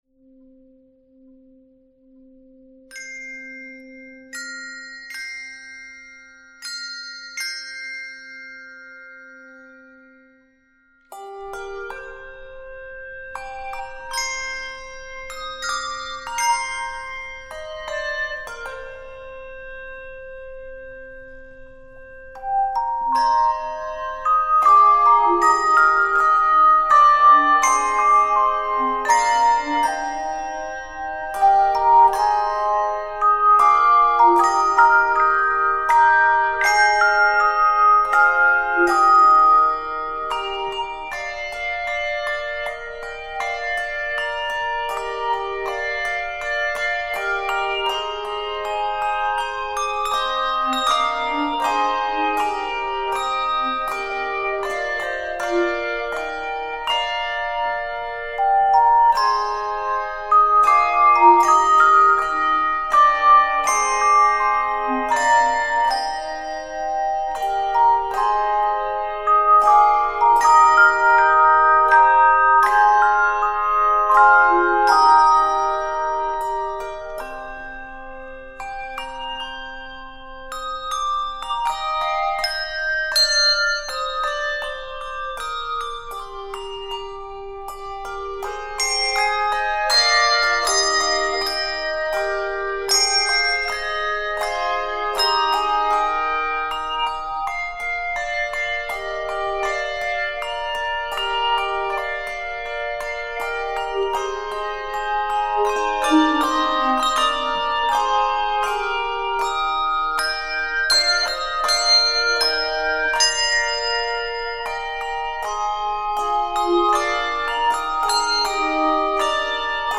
hauntingly beautiful melody
Key of c minor.